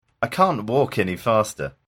イギリス英語では“can’t”とは「カン（ト）」と聞こえます。
先ほどイギリス英語では“can’t”とは「カン（ト）」と聞こえるといいました。